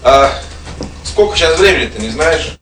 Worms speechbanks
Comeonthen.wav